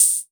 Techno Open Hat 01.wav